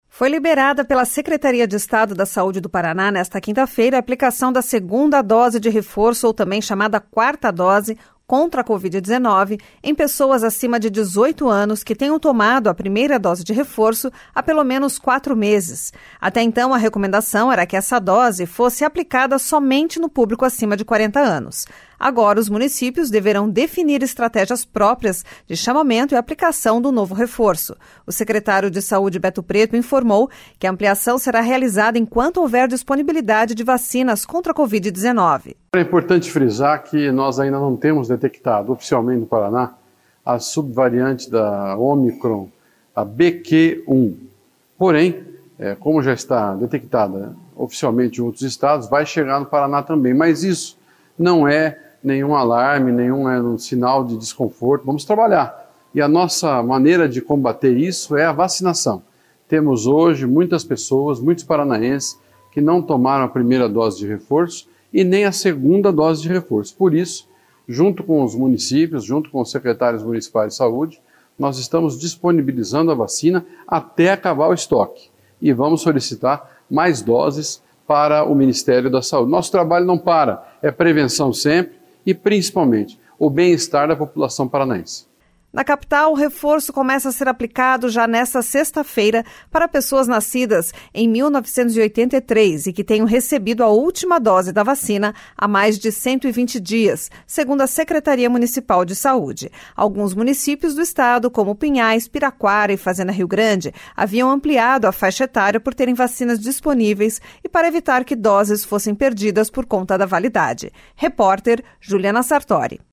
O secretário de saúde Beto Preto informou que a ampliação será realizada enquanto houver disponibilidade de vacinas contra a Covid-19.